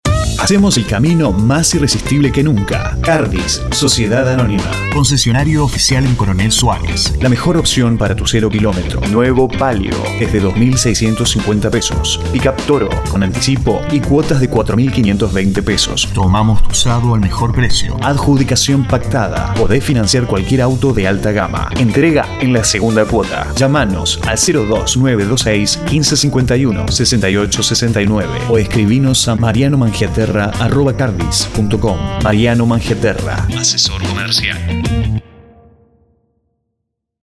Mic AT 2500 USB Edit SoundForge Pro 11 Adobe Audition 6
spanisch Südamerika
Sprechprobe: Werbung (Muttersprache):